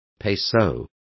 Complete with pronunciation of the translation of peso.